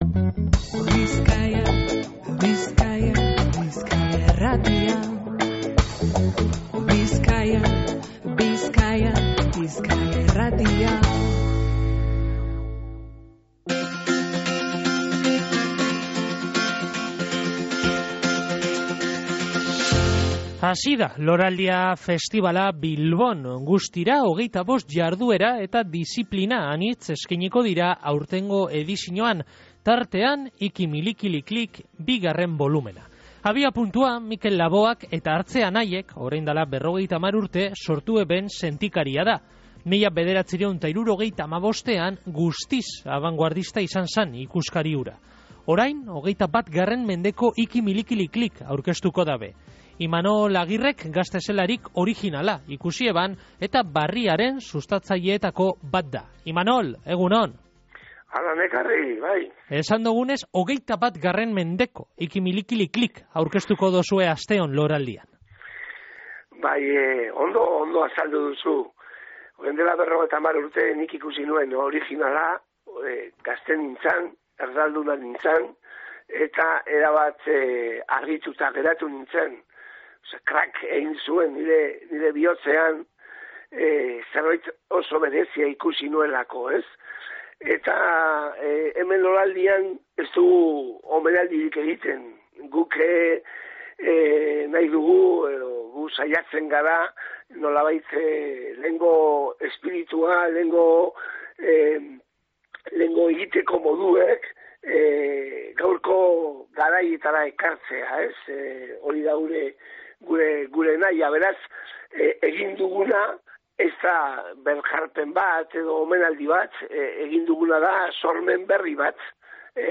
egin dogu berba gaurko Goizeko Izarretan irratsaioan.